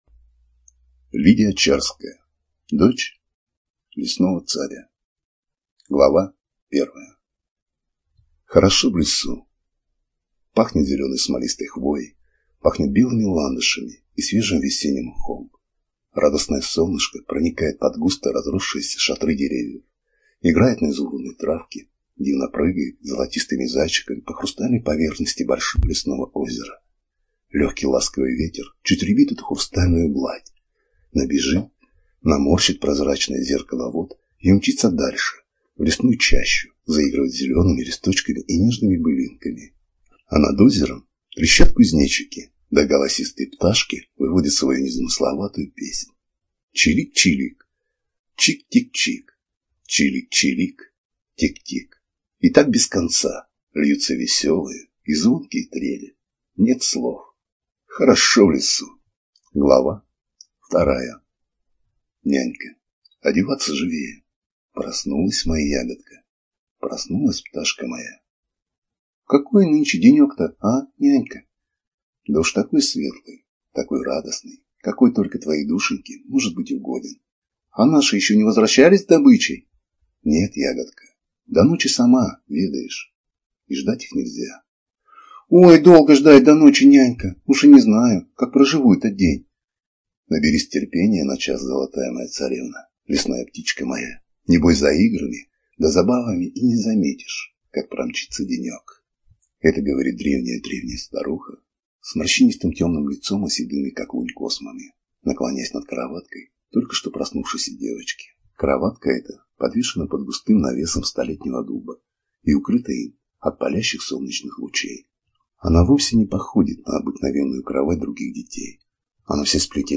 ЖанрСказки